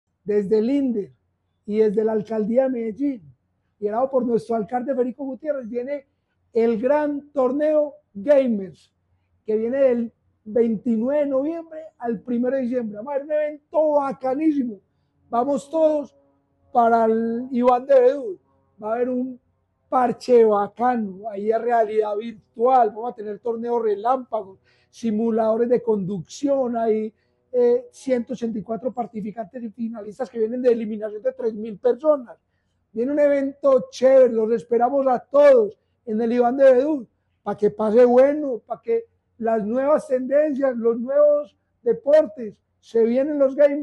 Palabras de Eduardo Silva Meluk., director del Inder Del 29 de noviembre al 1 de diciembre, la capital antioqueña vivirá la final de los Gamers Med en el Coliseo Iván de Bedout.